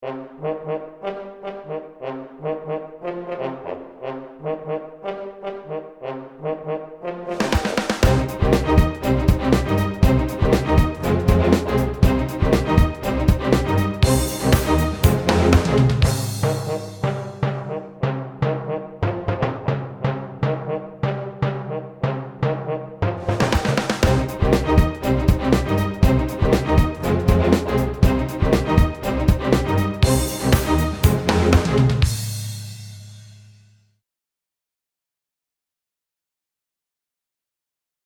My first attempt using symphonic orchestra loops.